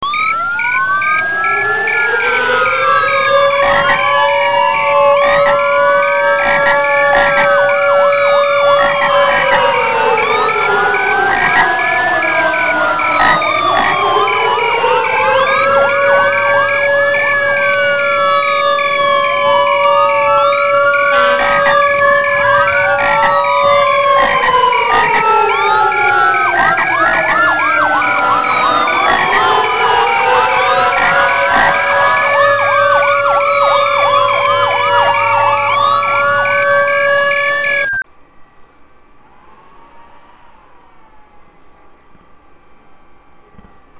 I could, however, create audible dusky sirens: [Download] Not quite what Ulysses heard, right.
duskysirensaudi62234.mp3